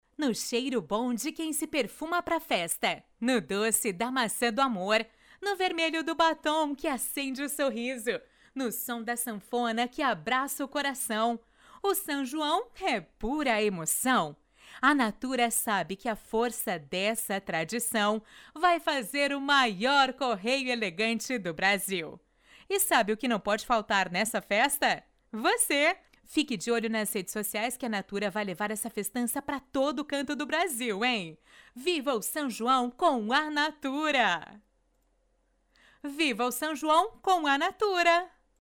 Institucional:
VOZ BEM MACIA E SUAVE!!!